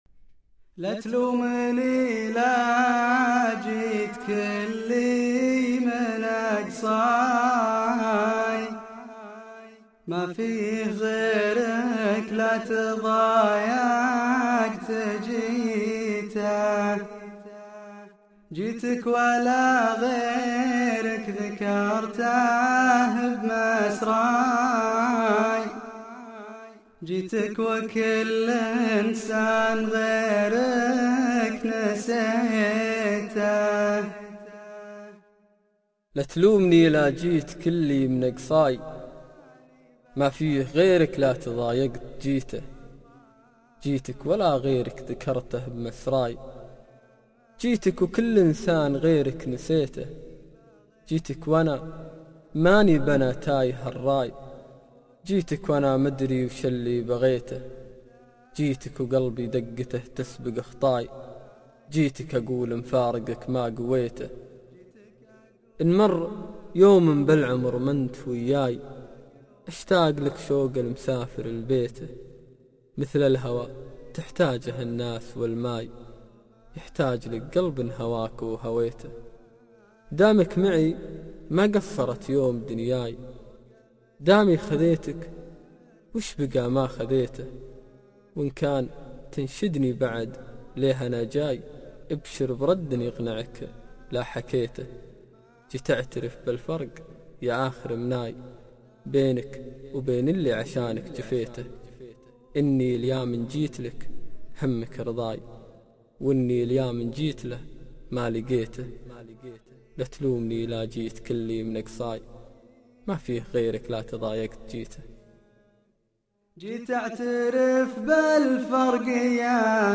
شيله + إالقاء